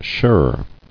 [shirr]